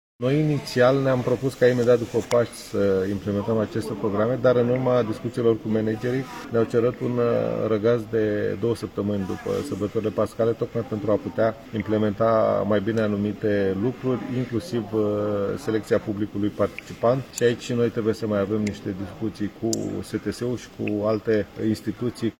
Ministrul Culturii, Bogdan Gheorghiu, într-o transmisiune de pe scena Teatrului Național București: